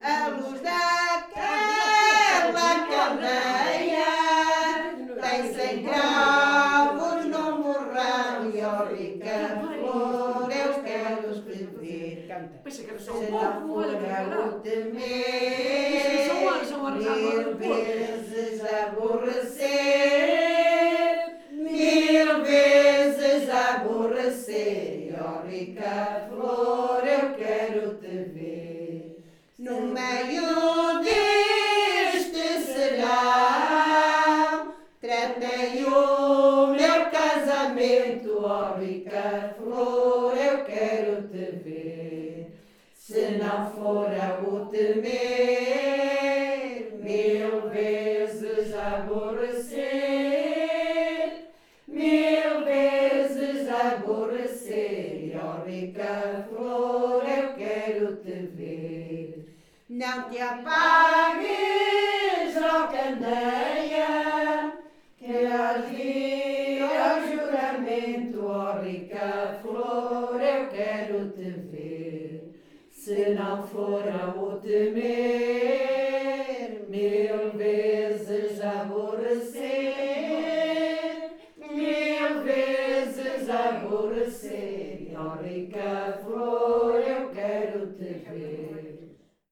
Grupo Etnográfico de Trajes e Cantares do Linho de Várzea de Calde - Ensaio - A luz daquela candeia.
NODAR.00702 – Grupo Etnográfico de Trajes e Cantares do Linho – A luz daquela candeia (Várzea de Calde, Viseu)